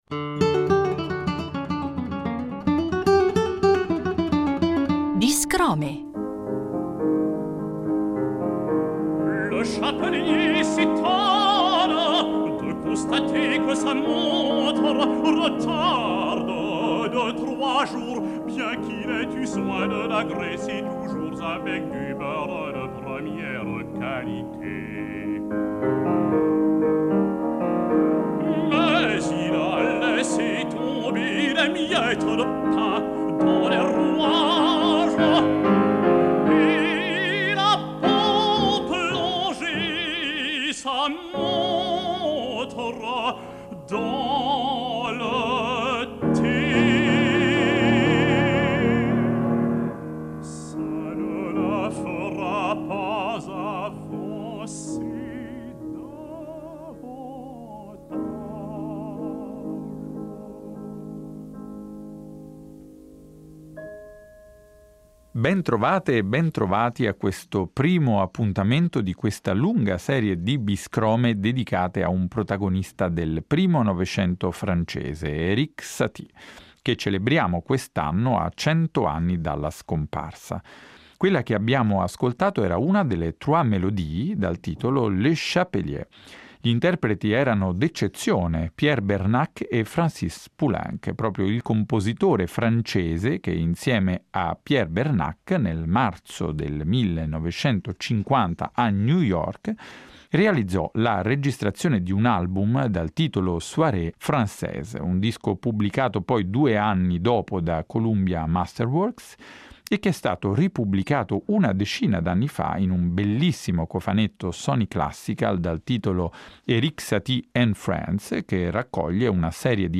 il pianista e produttore discografico